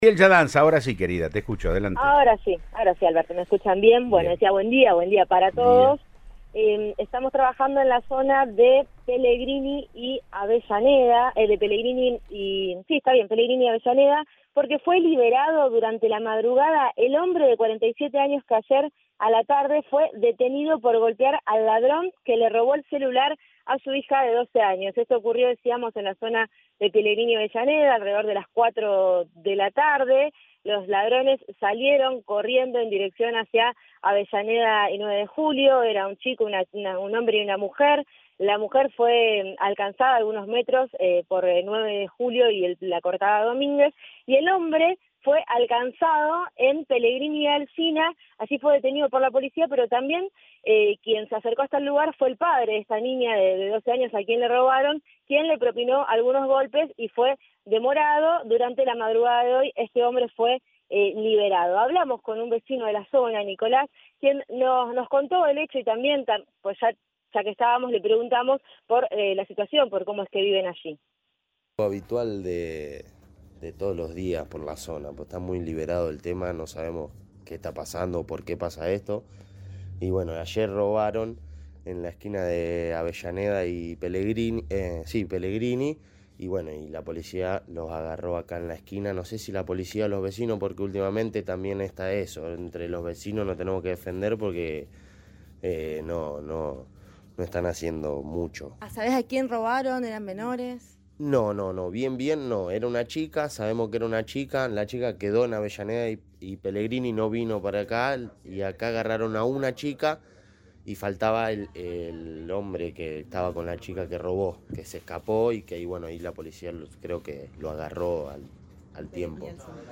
“Es cuestión de todos los días porque la zona que esta liberada”, le dijeron vecinos al móvil de Cadena 3 Rosario.